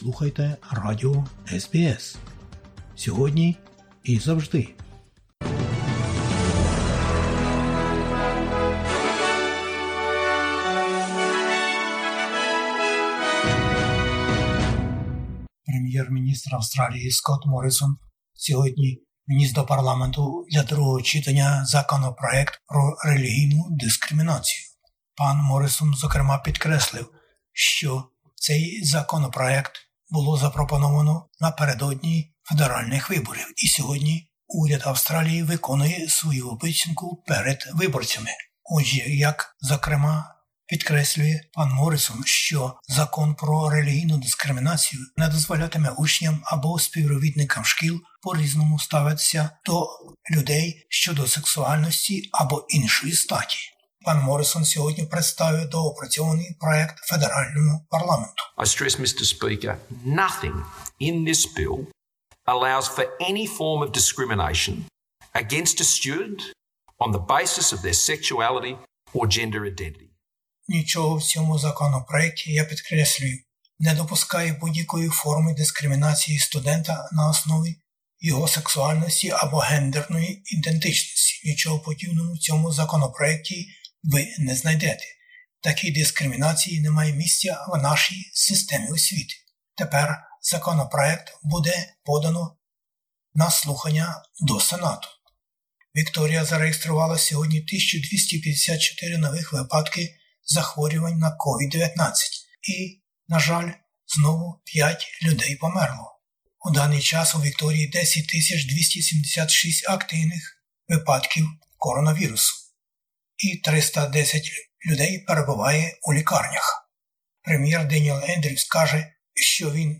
Бюлетень новин українською мовою. Новий законопроєкт Religious Discrimination Bill 2021 представлено у Парламенті Австралії, де уже діють а Sex Discrimination Act, a Racial Discrimination Act, a Disability Discrimination Act and an Age Discrimination Act.